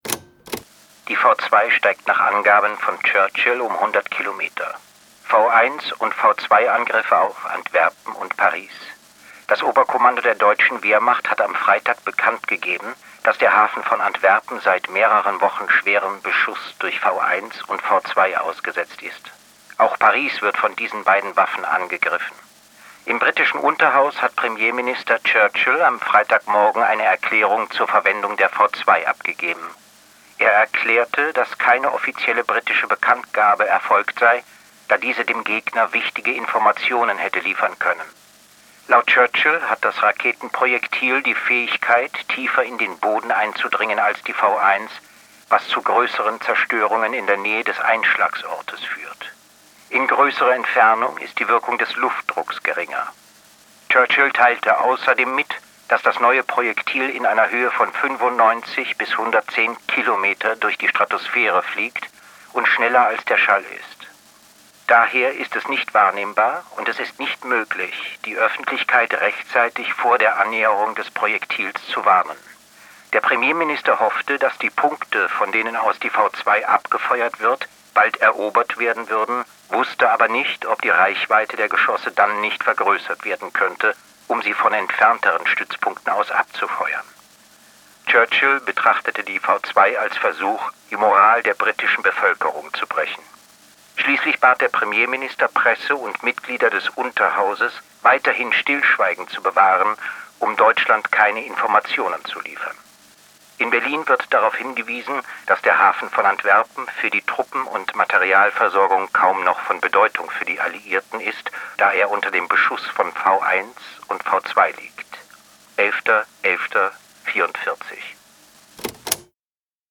voorgedragen door Sebastian Blomberg